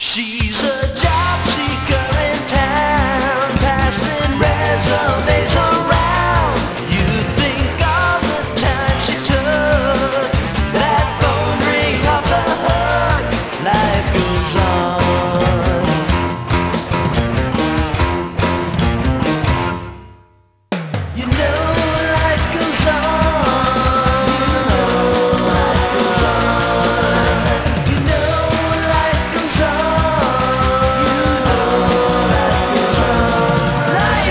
Medium Pop